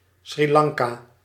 Ääntäminen
US : IPA : [ʃɹiː ˈlɑːŋ.kə]